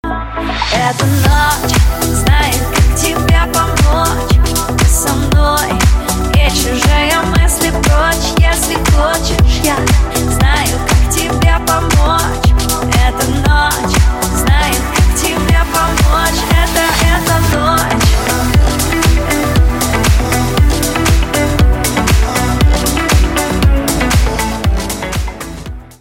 • Качество: 320, Stereo
поп
женский вокал
dance
чувственные